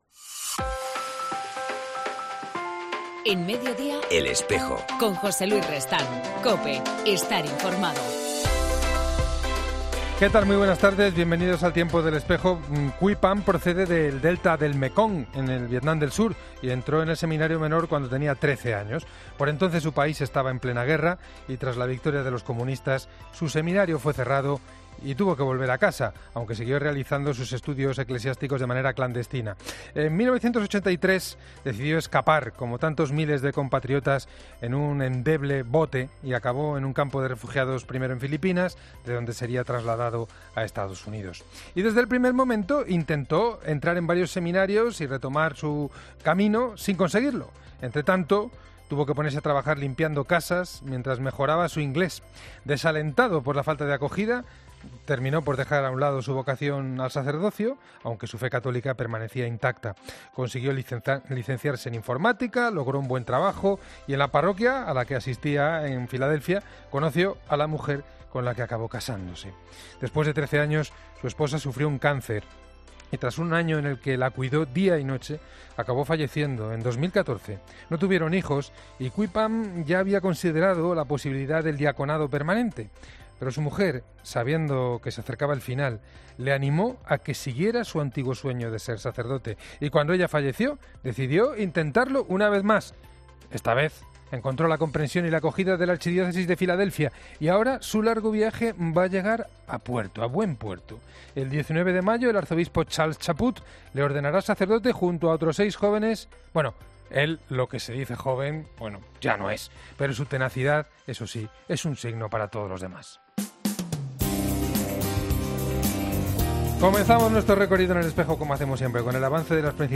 AUDIO: En el contexto de la Jornada de Oración por las Vocaciones y de las Vocaciones nativas, que celebraremos el próximo domingo, entrevistamos en...